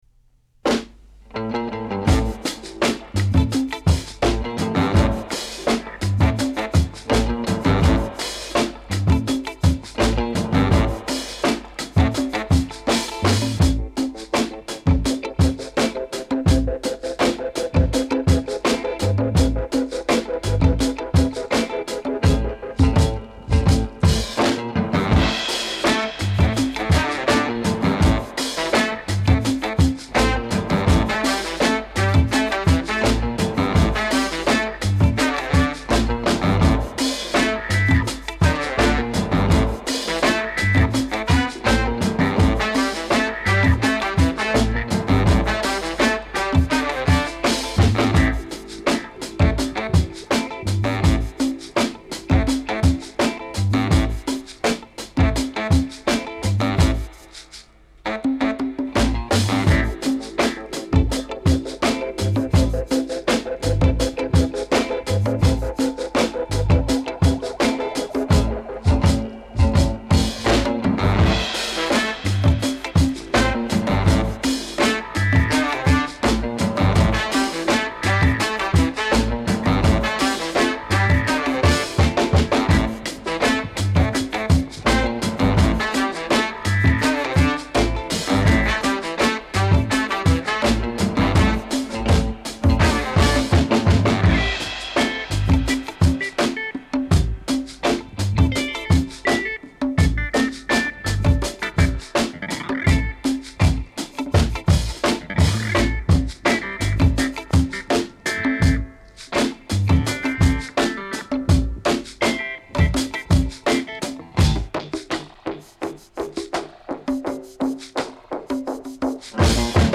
Genre : Funk, Soul